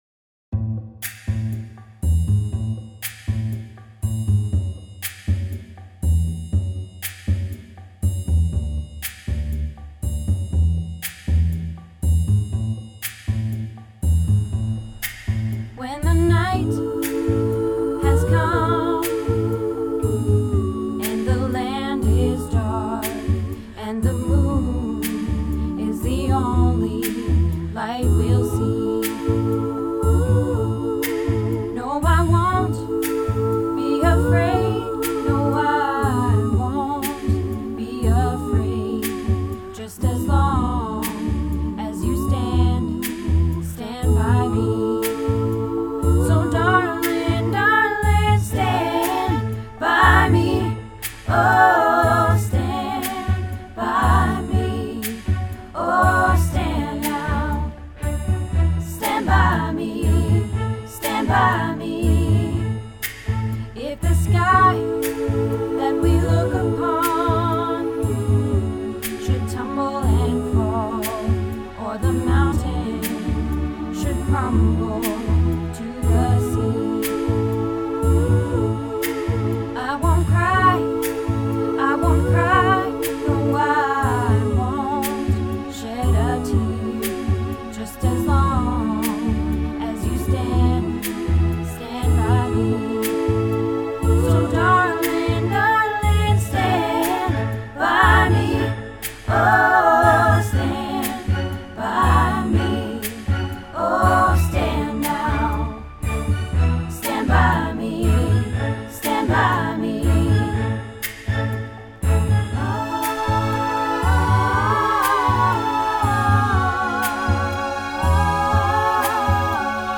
Stand By Me - Practice